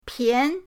pian2.mp3